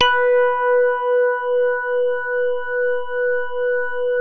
JAZZ MID  H3.wav